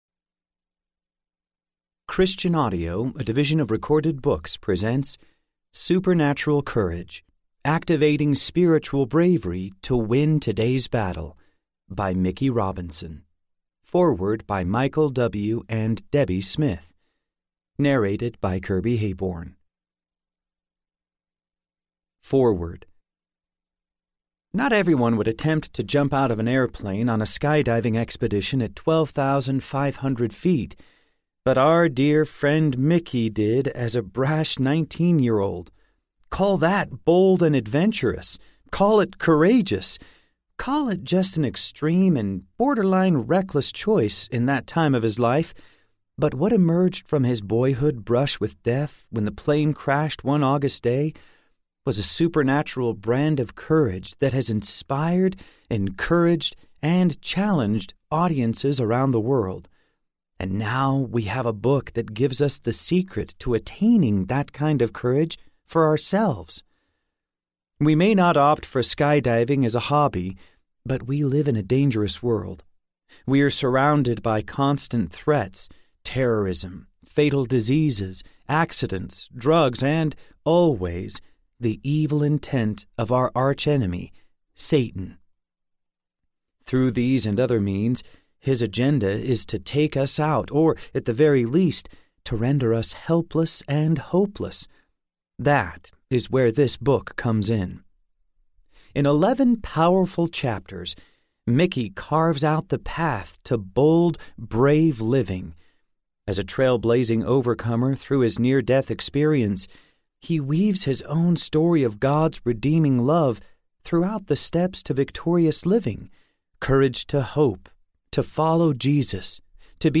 Supernatural Courage Audiobook
6.6 Hrs. – Unabridged